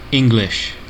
Ääntäminen
Ääntäminen : IPA : /ˈɪŋ.ɡlɪʃ/ US : IPA : [ˈɪŋ.ɡlɪʃ] UK : IPA : [ˈɪŋ.ɡlɪʃ] Lyhenteet en Eng.